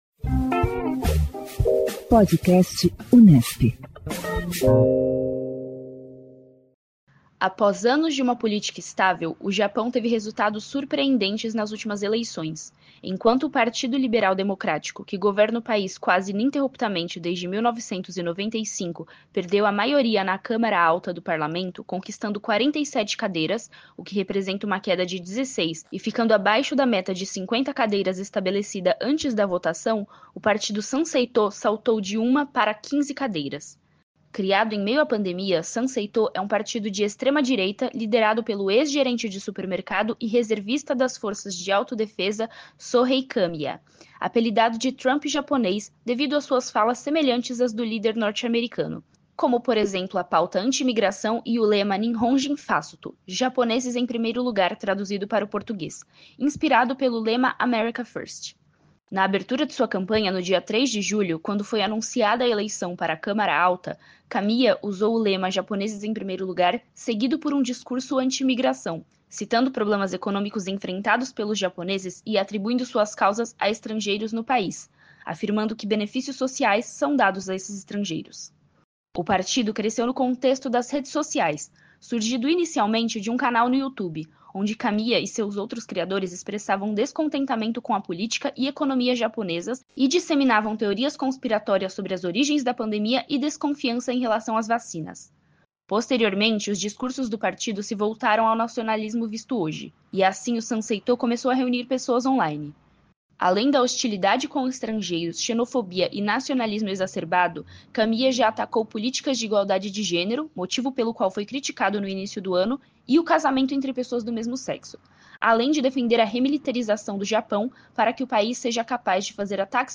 O Podcast Unesp divulga semanalmente entrevistas com cientistas políticos sobre as mais variadas pautas que englobam o universo político e as relações internacionais.